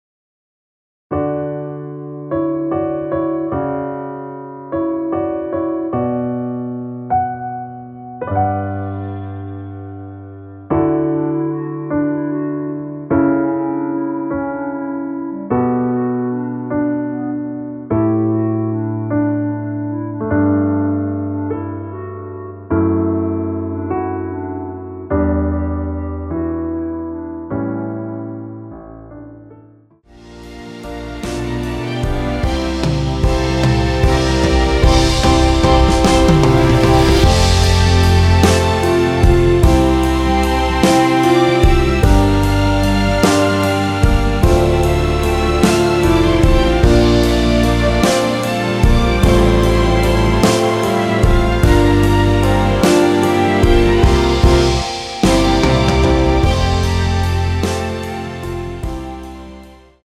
원키에서(-3)내린 멜로디 포함된 MR입니다.
앞부분30초, 뒷부분30초씩 편집해서 올려 드리고 있습니다.
(멜로디 MR)은 가이드 멜로디가 포함된 MR 입니다.